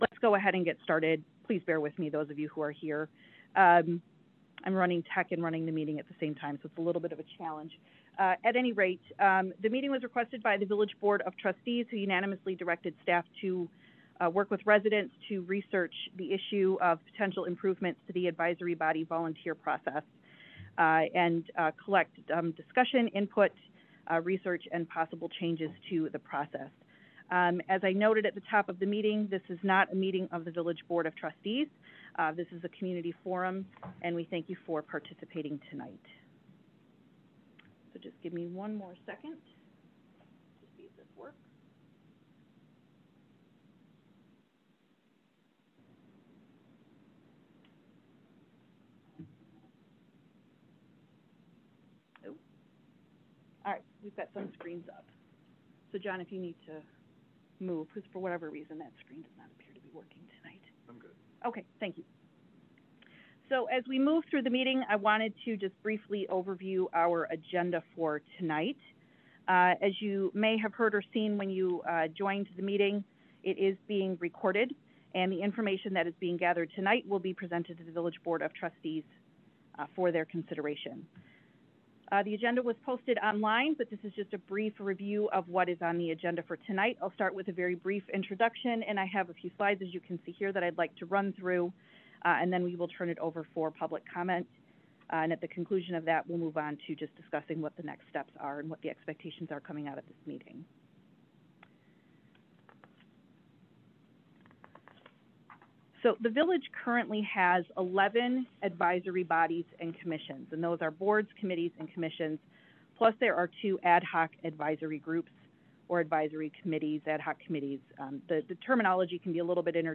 Public Meeting – Advisory Body Volunteer Process